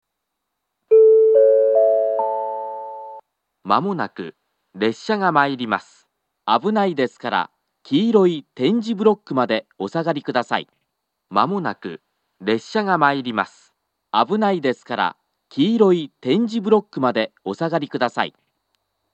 スピーカーからは接近放送が、電子電鈴からはベルが流れます。
２０２５年４月には再度放送装置が更新されているのが確認され、「黄色い点字ブロックまで」と言う放送になっています。
２番線接近放送